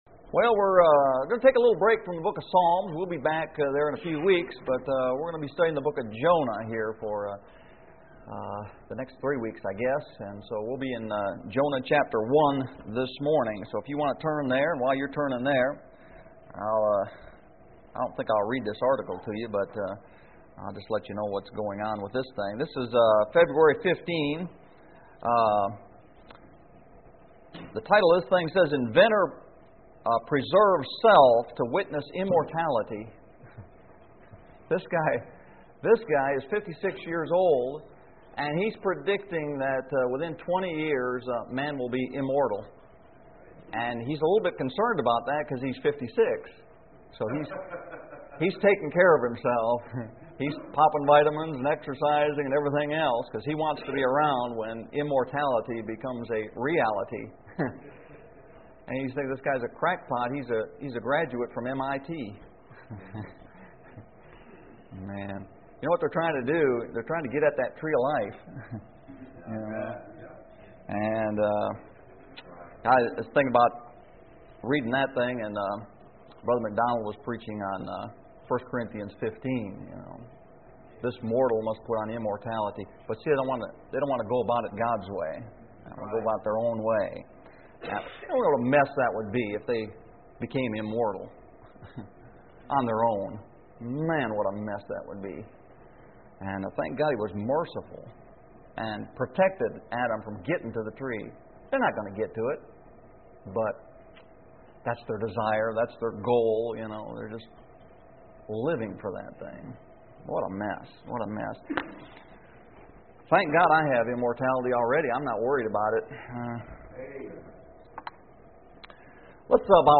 Jonah 1 Sunday School Lesson